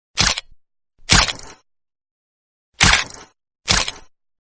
Звуки Человека-Паука, паутины
Человек паук выпускает паутину